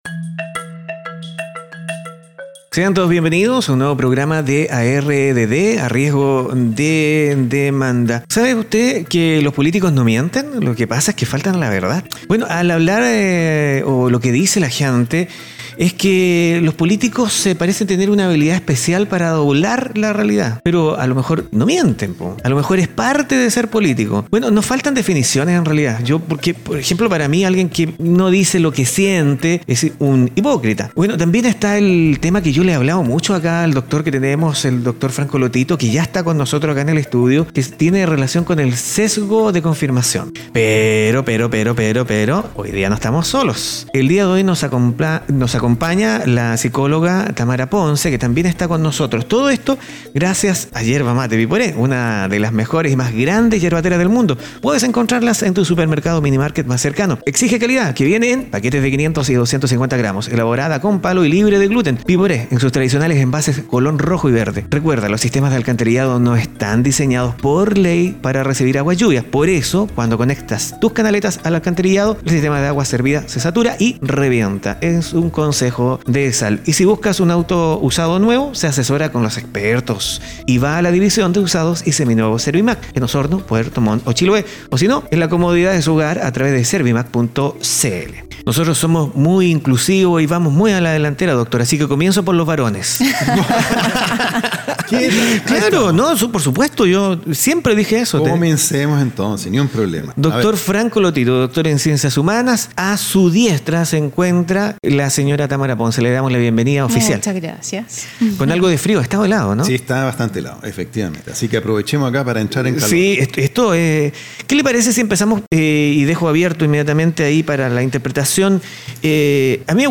Una conversación más que interesante que invitamos a compartir.